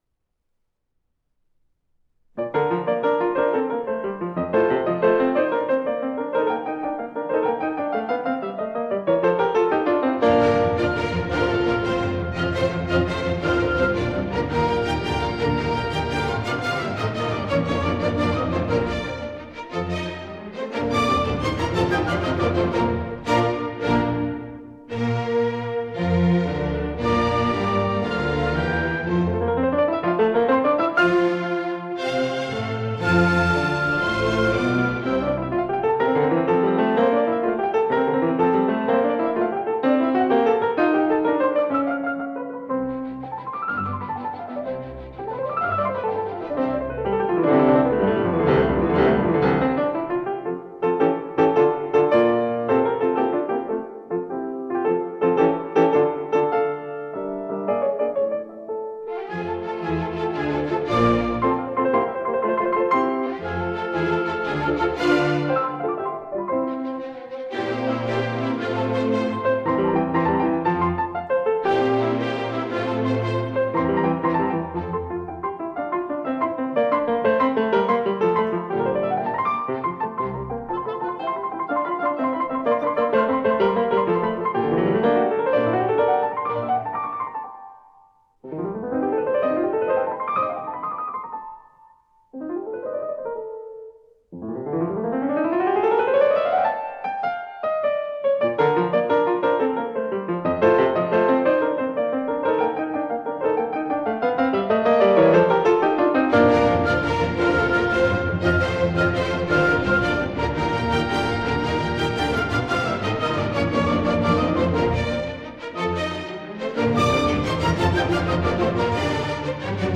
Orchestra
Recorded at Severance Hall, Cleveland, April 16, 1961